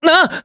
Evan-Vox_Damage_jp_03_b.wav